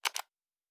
Sci-Fi Sounds / Interface / Click 14.wav
Click 14.wav